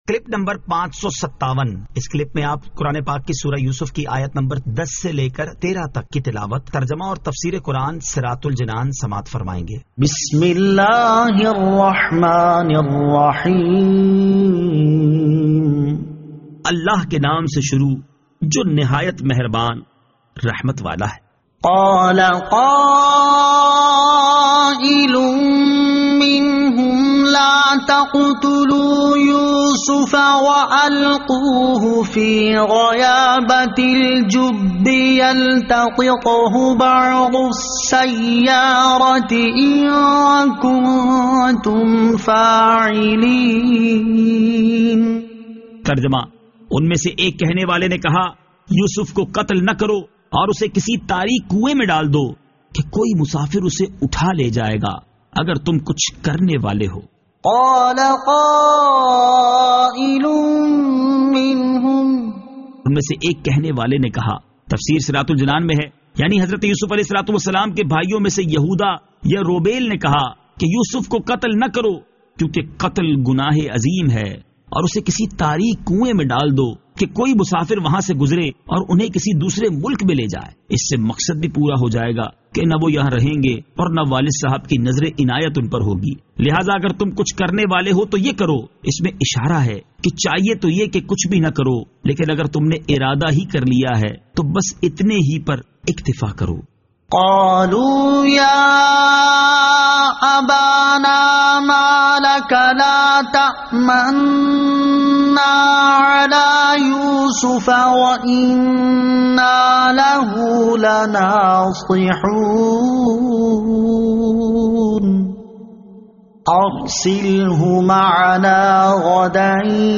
Surah Yusuf Ayat 10 To 13 Tilawat , Tarjama , Tafseer